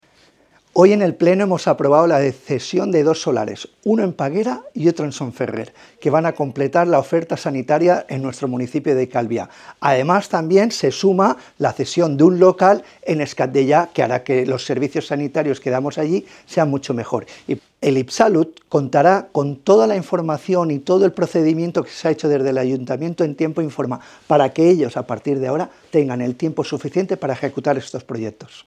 declas-alcalde-juan-antonio-amengual.mp3